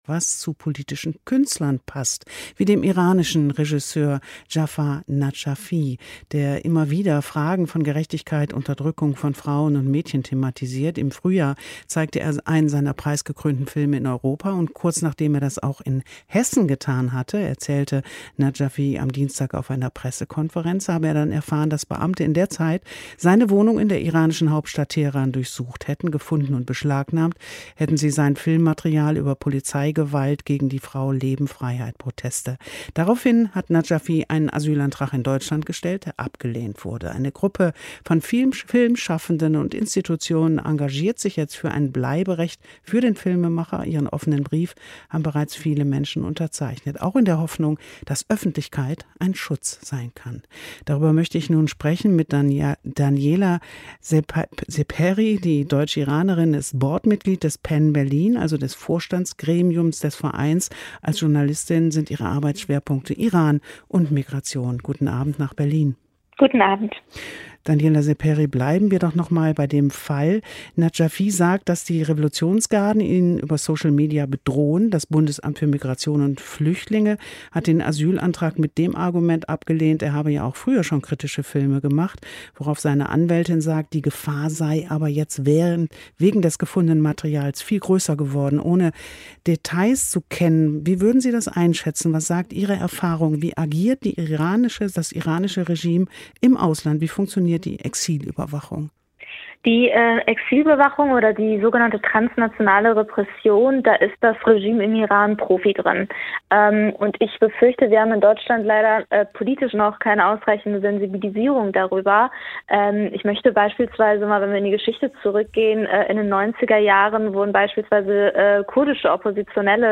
WDR 3, Resonanzen, Gespräch